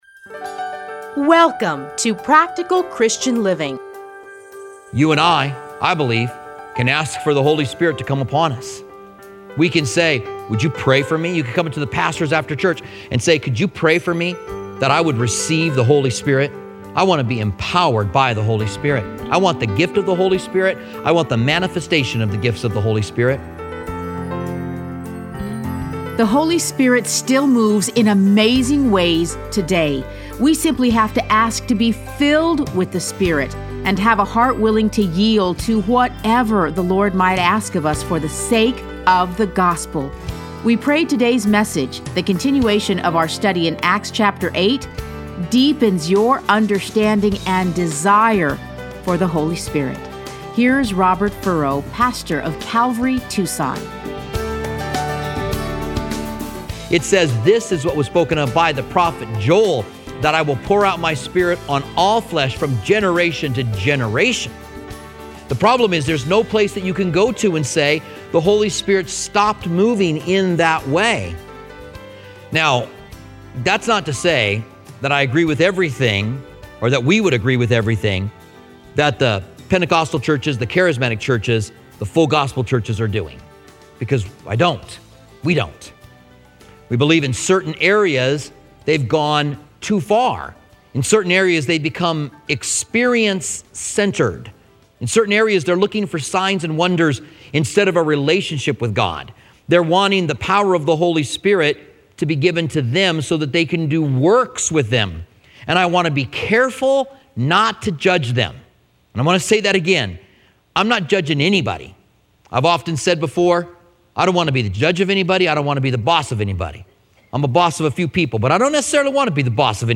Listen to a teaching from Acts 8.